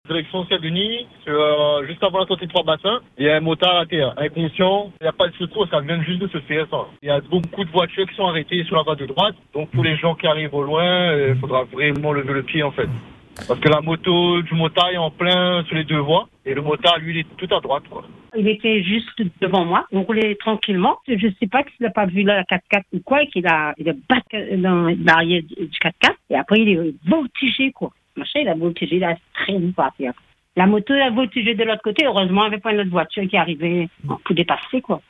Des conducteurs présents au moment des faits témoignent aujourd’hui.